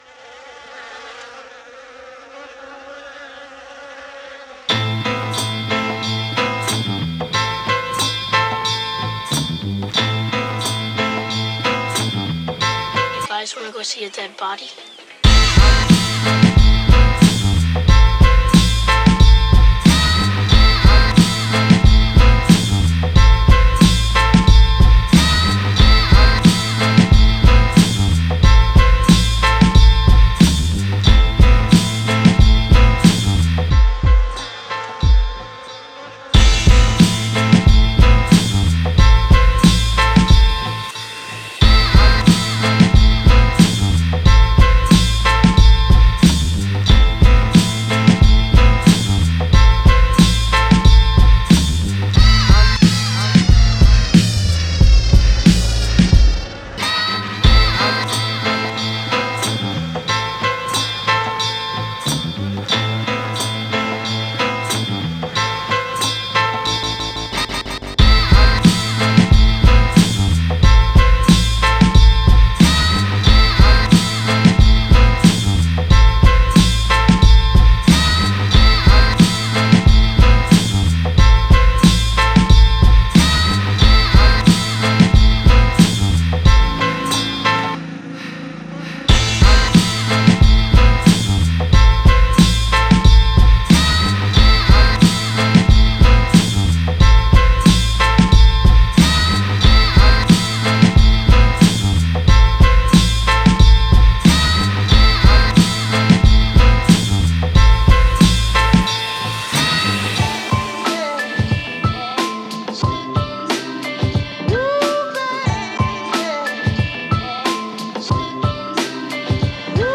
Instrumental Project
Genre: Hip-Hop.